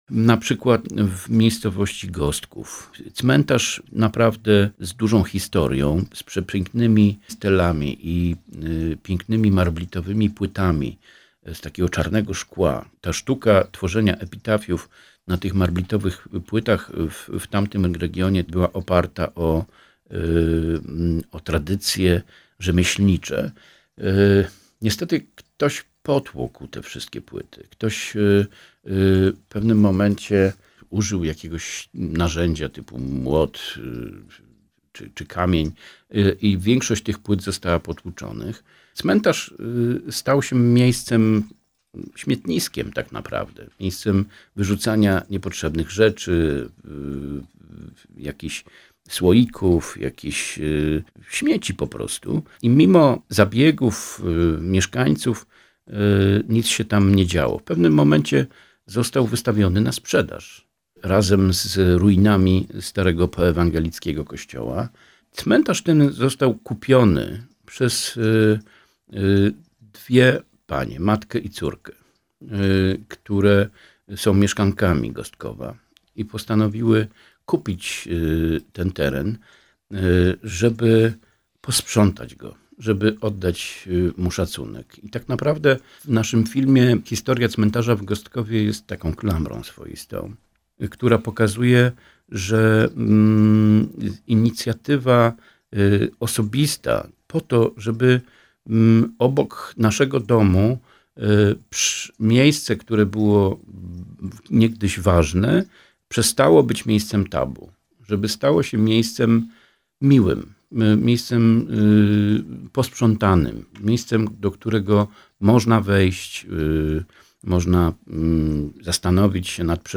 Cała rozmowa w piątek 5 grudnia w audycji „Przystanek Kultura” po godz. 10:10.